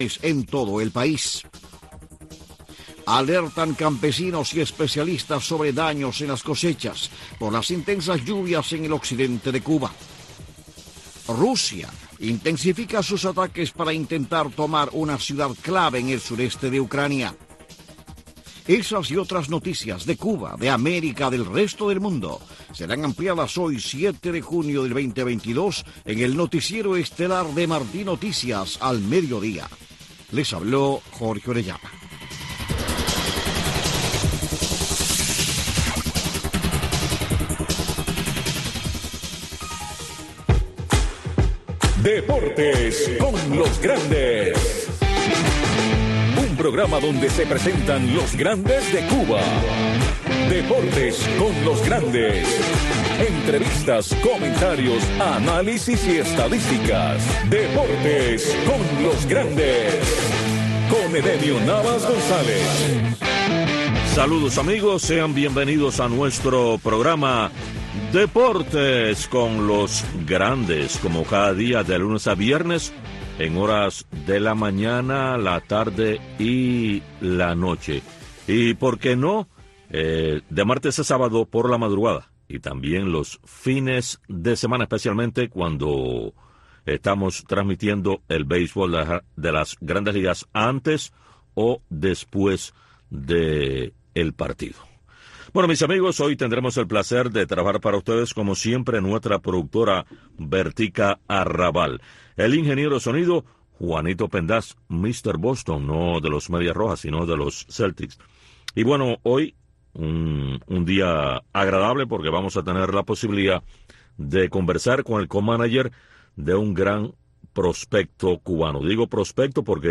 Entrevistas, comentarios y análisis de los grandes acontecimientos del deporte.